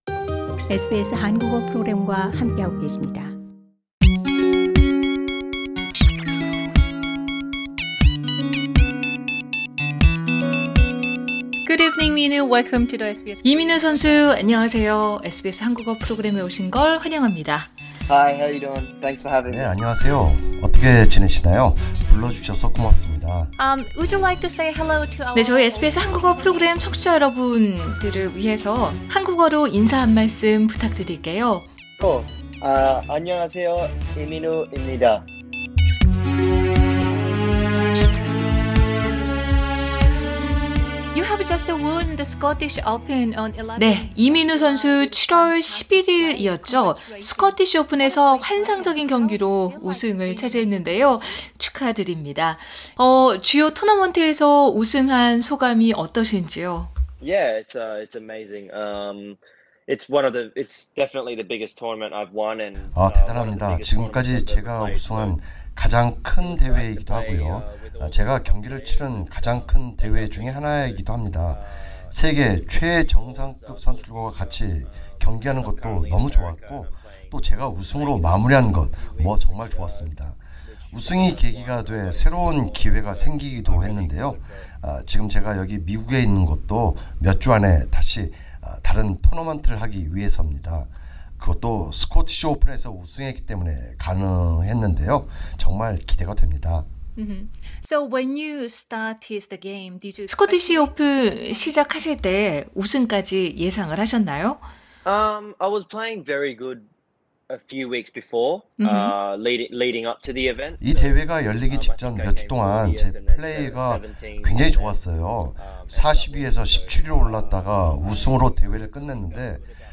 [단독 인터뷰-이민우] “세계 정상이 목표입니다”